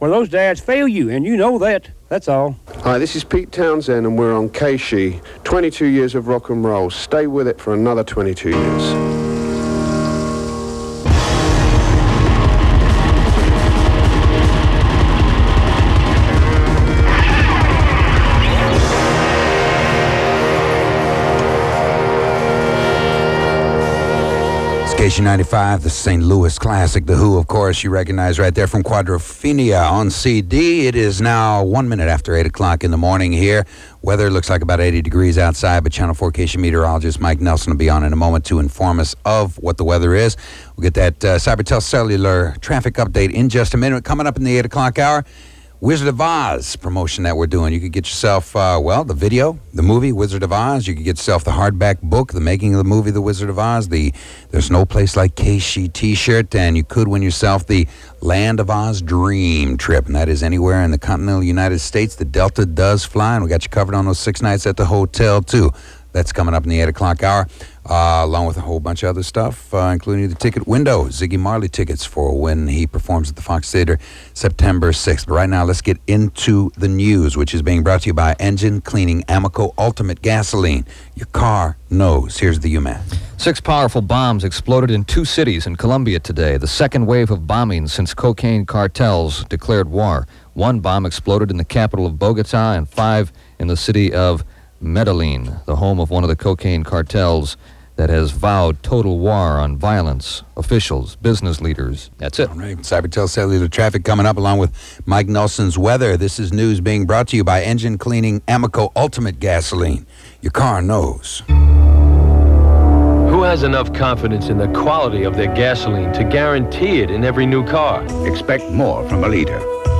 KSHE Morning Zoo Aircheck · St. Louis Media History Archive